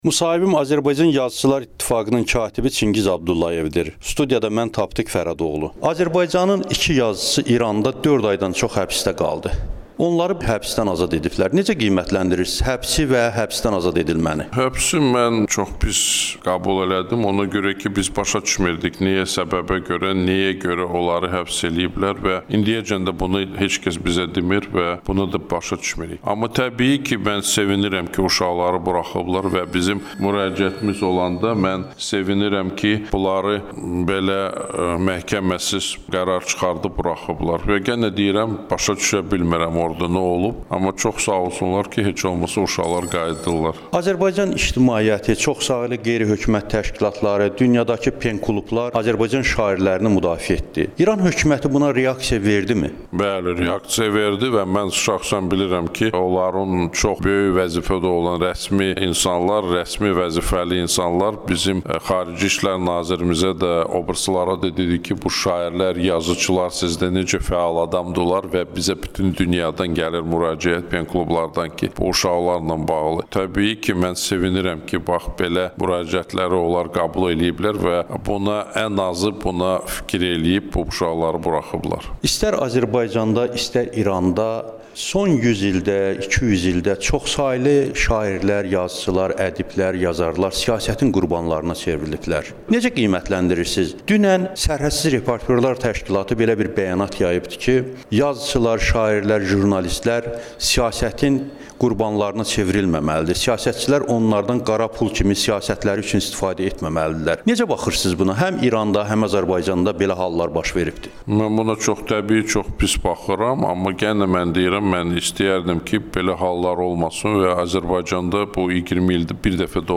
Çingiz Abdullayevlə müsahibə